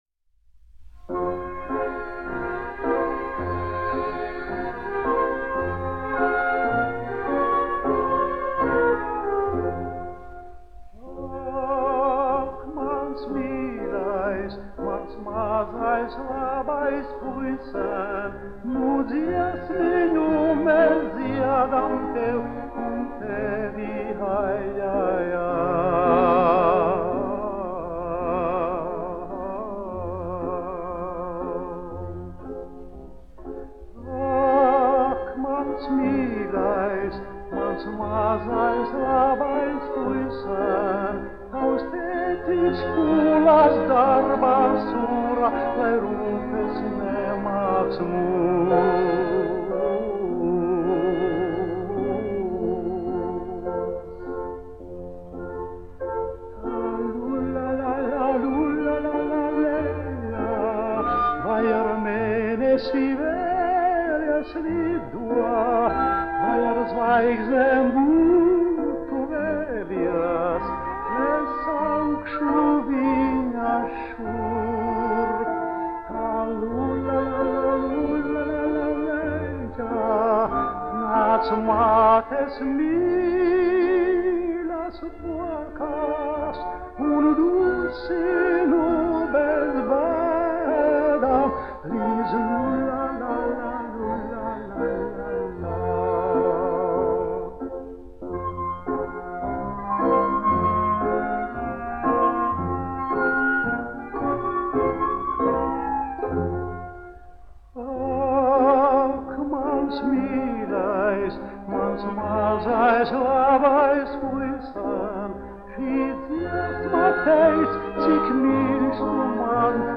1 skpl. : analogs, 78 apgr/min, mono ; 25 cm
Dziesmas (augsta balss) ar orķestri
Latvijas vēsturiskie šellaka skaņuplašu ieraksti (Kolekcija)